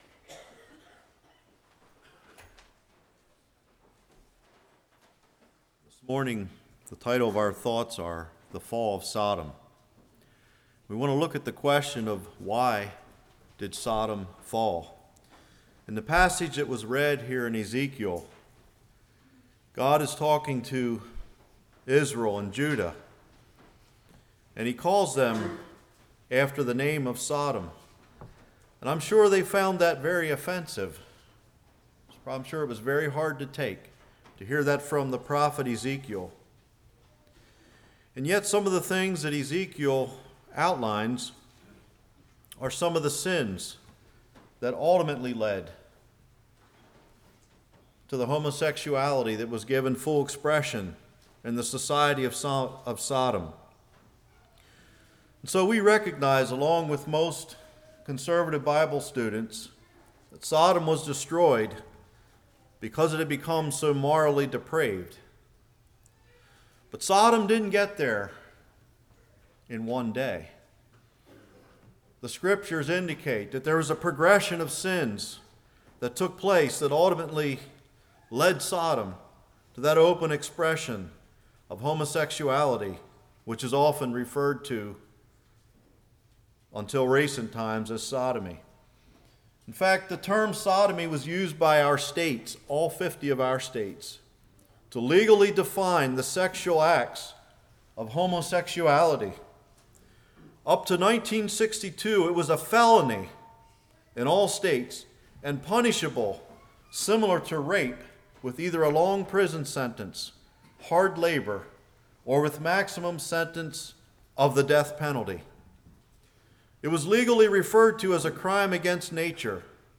Ezekiel 16:44-50 Service Type: Morning Can it come into the Church?